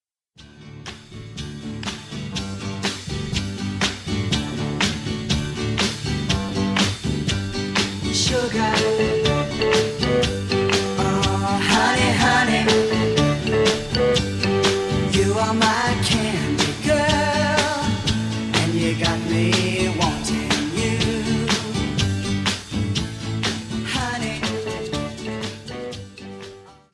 Time for some Bubblegum Pop.
It’s a great groove for the harmonica.